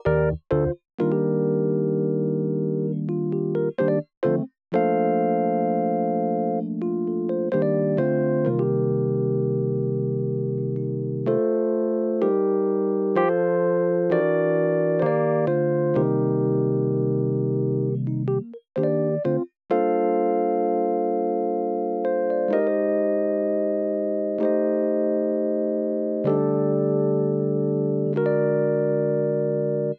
07 rhodes D.wav